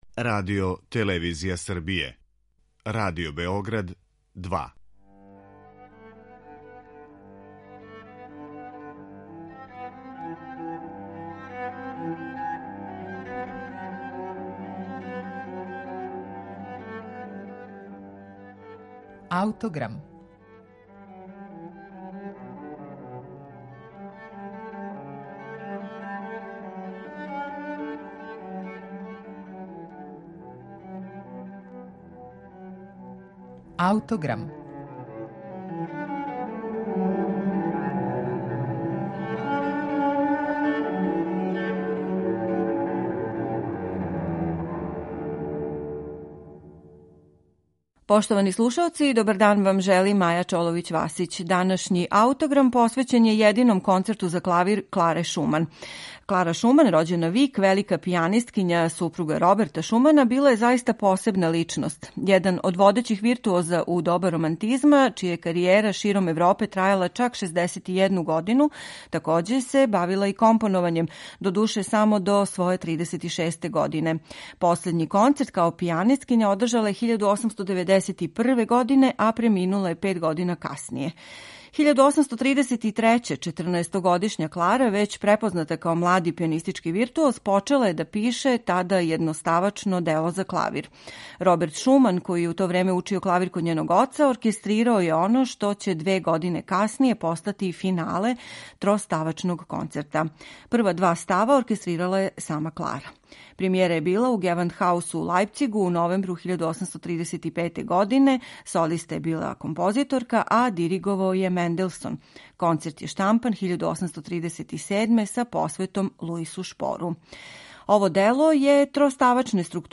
Данашњи Аутограм је посвећен једином концерту за клавир Кларе Шуман, композицији коју је ова уметница, један од највећих клавирских виртуоза 19. века, написала када је имала 14 година.
Премијера Концерта, чија се три става нижу без паузе и који плени лакоћом, неоптерећујућим виртуозитетом и младалачким полетом, била је у Гевандхаусу у Лајпцигу 1835. године.